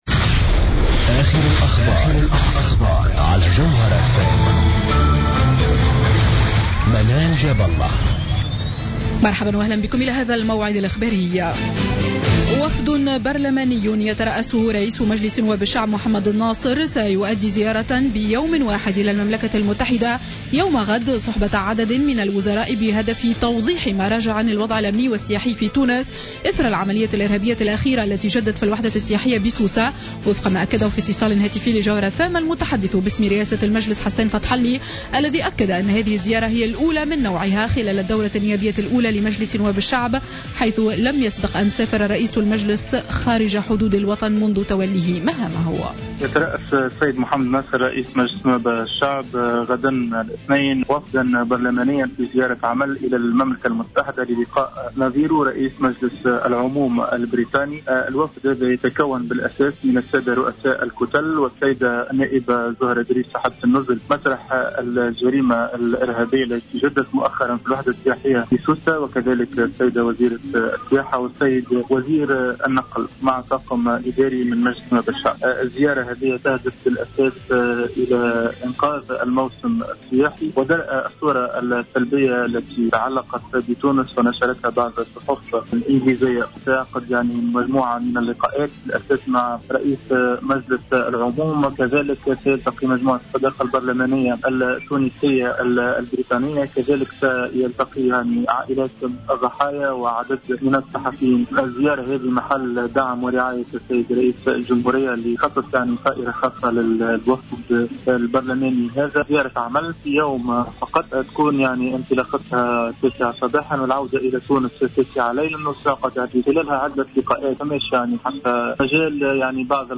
نشرة أخبار السابعة مساء ليوم الاحد 19 جويلية 2015